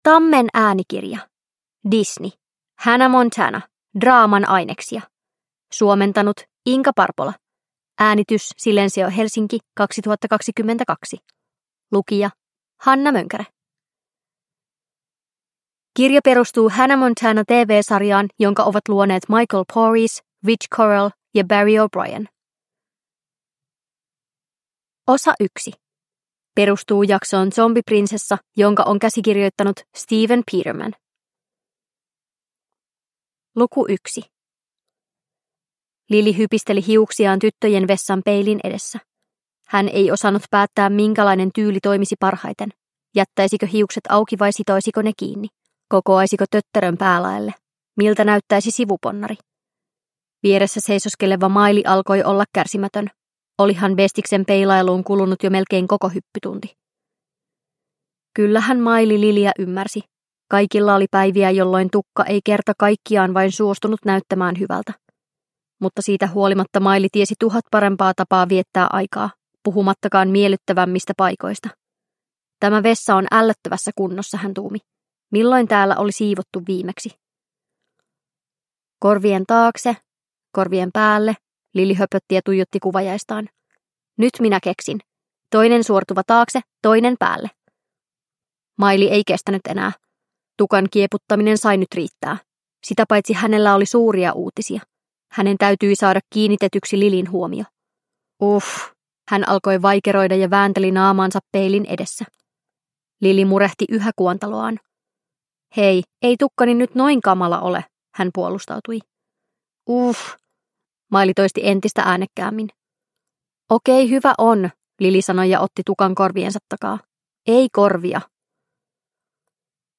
Hannah Montana. Draaman aineksia – Ljudbok – Laddas ner